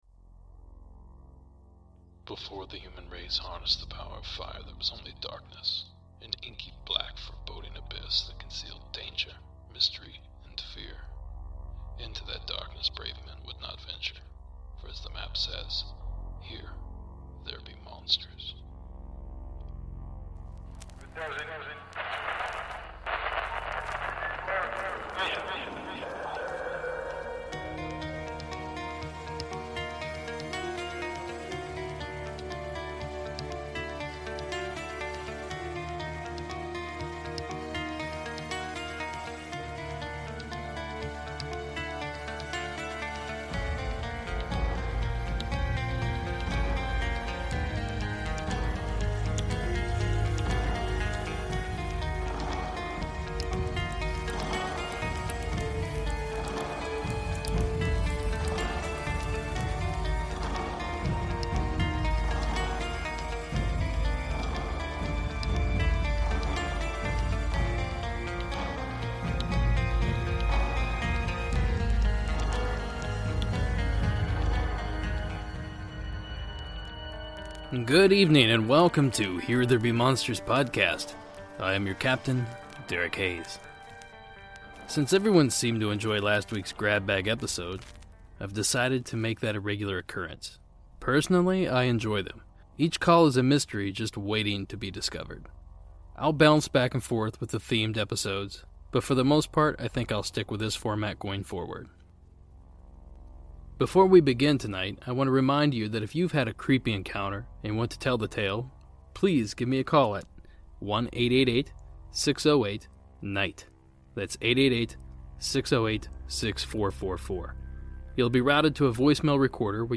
Sn. 1 Ep. 13 - A collection of creepy calls